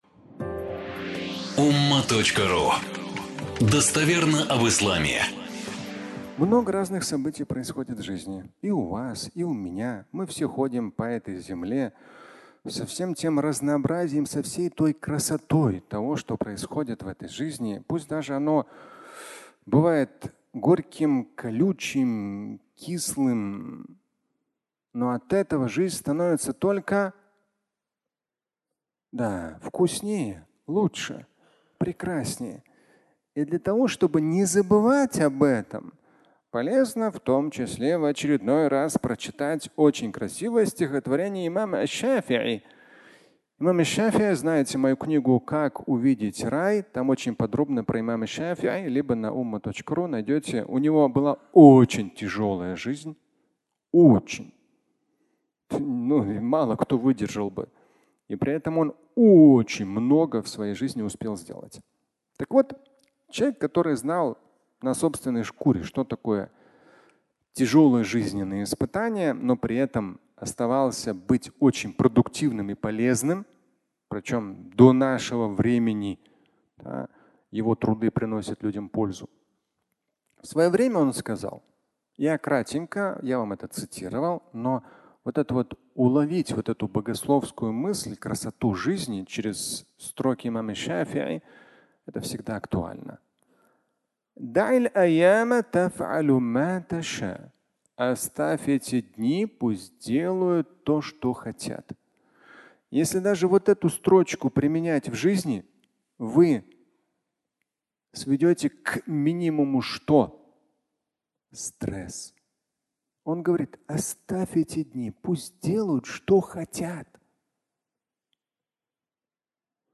Чувство безопасности (аудиолекция)